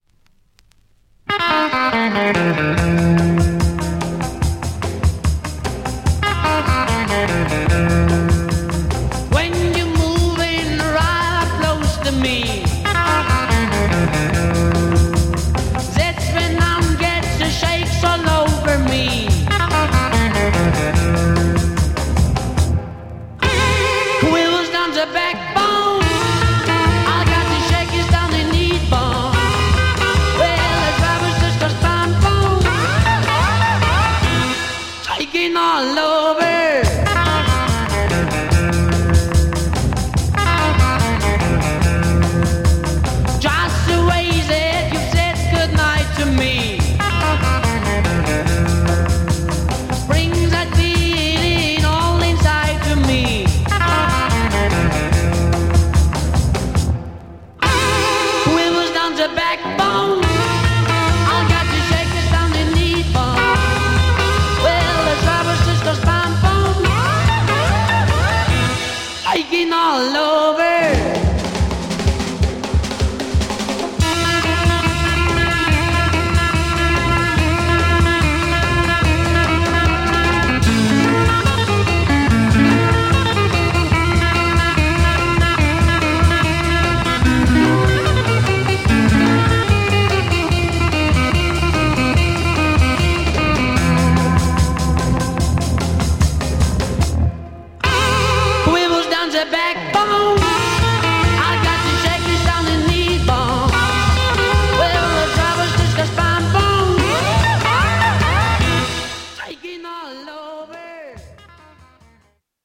German Garage Beat single
Classic German beat garage single.